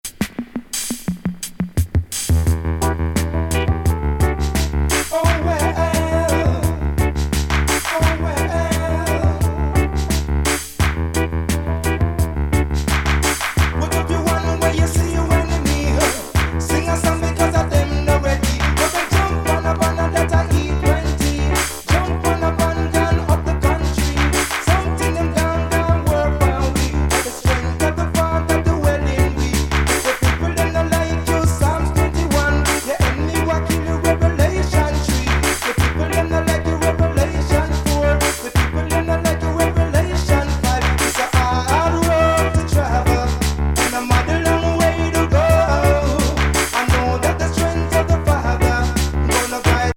追い風吹きまくり期のコンピュータ・トラック満載86年作！
エレクトロ・レゲー・ディスコな「LOVE